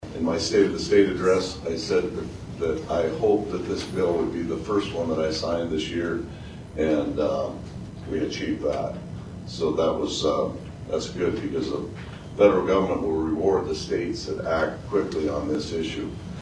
HE THANKED STATE LAWMAKERS FOR PASSING THE BILL QUICKLY: